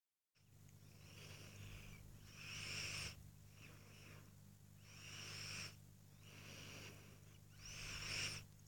Звуки сопения
Лёгкое сопение